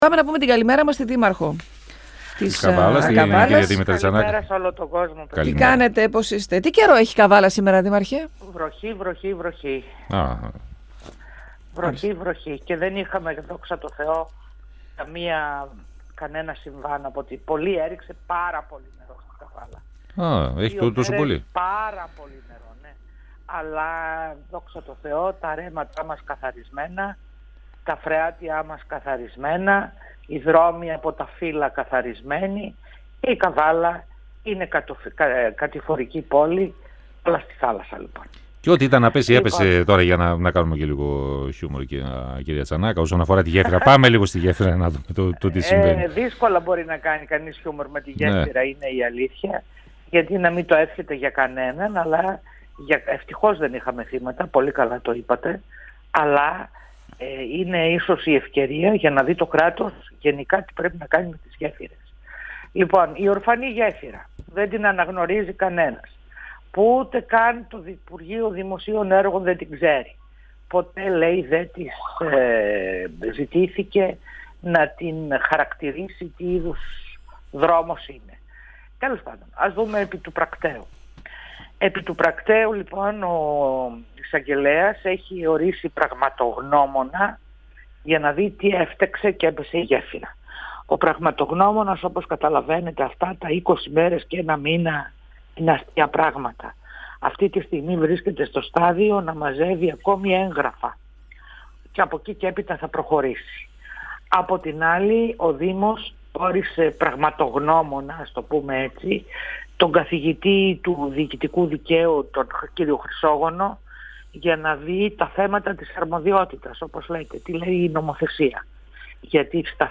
Λύση στο πρόβλημα που δημιουργήθηκε από την κατάρρευση της γέφυρας στην Καβάλα, αναζητούν οι αρμόδιοι αλλά όλα δείχνουν ότι αυτή θα καθυστερήσει, καθώς δήμος και περιφέρεια Ανατολικής Μακεδονίας και Θράκης αποποιούνται την ευθύνη. Στην πραγματογνωμοσύνη, που διενεργείται με εντολή του εισαγγελέα, για τα αίτια της κατάρρευσης αναφέρθηκε η δήμαρχος Καβάλας, Δήμητρα Τσανάκα, μιλώντας στον 102FM της ΕΡΤ3 και υποστήριξε ότι θα αναζητήσει μια λύση σε συνάντηση που θα έχει μεθαύριο με τον περιφερειάρχη.
102FM Συνεντεύξεις ΕΡΤ3